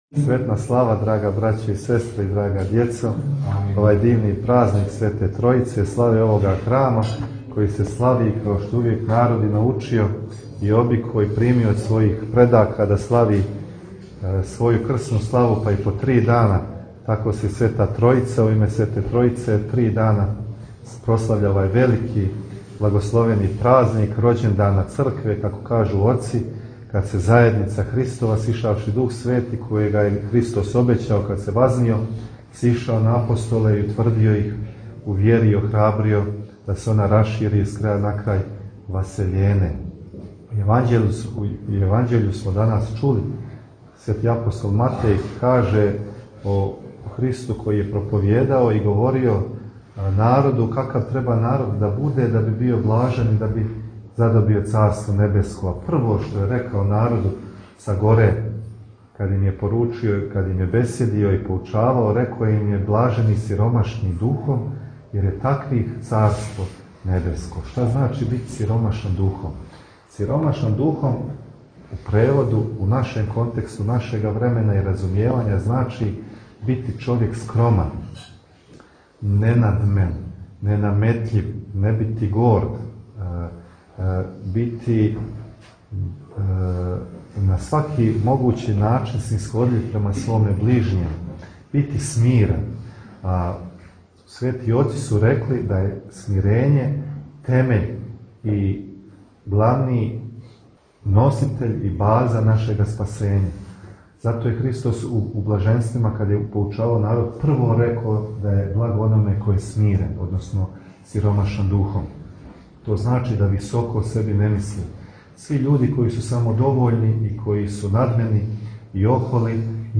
Епископ Методије богослужио у Прошћењу код Мојковца
На трећи дан празника Силаска Светог Духа на апостоле – Духовски уторак, празник Преподобног аве Јустина Ћелијског и Врањског, 14. јуна 2022. године Његово преосвештенство Епископ будимљанско-никшићки г. Методије служио је Свету архијерејску литургију, у цркви Свете Тројице, у Прошћењу код Мојковца.